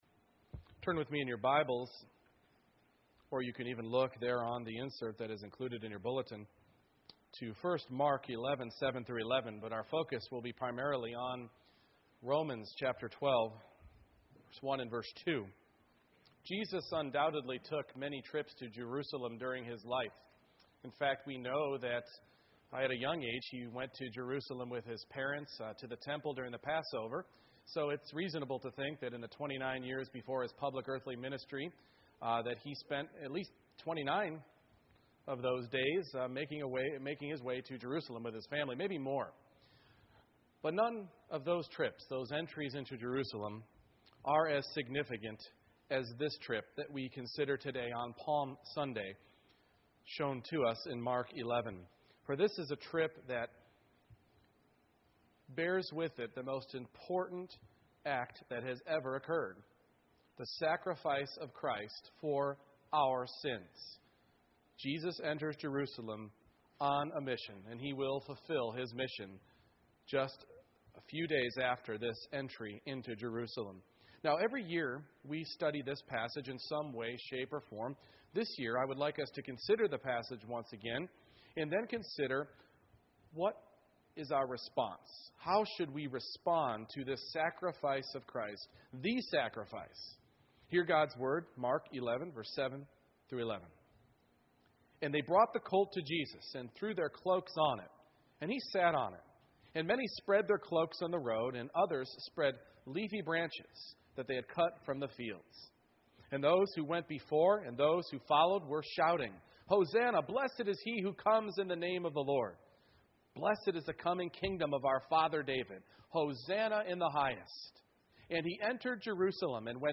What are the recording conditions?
Romans 12:1-2 Service Type: Morning Worship Jesus entered Jerusalem for the final time in order to be the sacrifice for our sins.